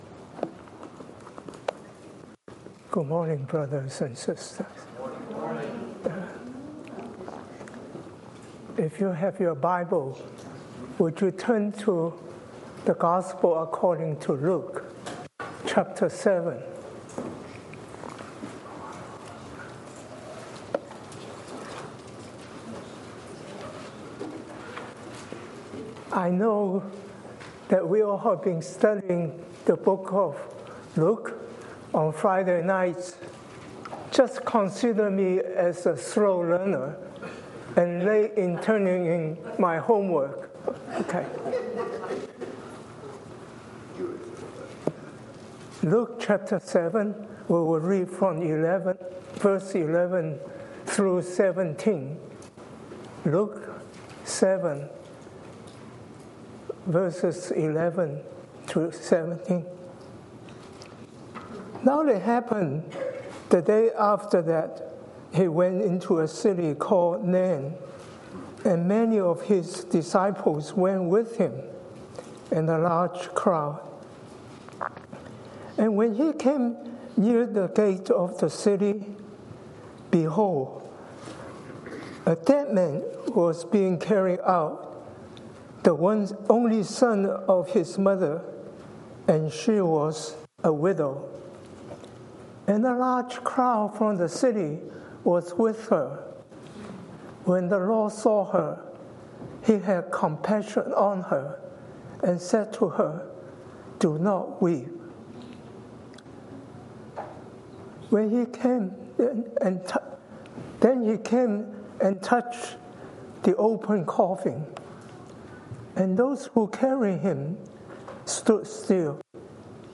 He encourages the congregation to trust in God's love, remembering that God's plans are far greater than human comprehension.